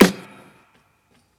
Clappy.wav